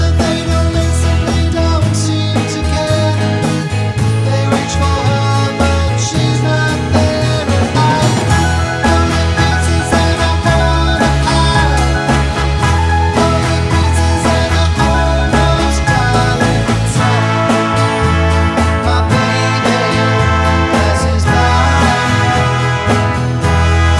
No Harmony Pop (1960s) 2:29 Buy £1.50